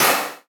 HammerStrong.wav